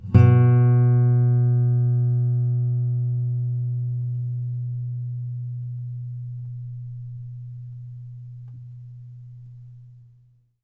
bass-05.wav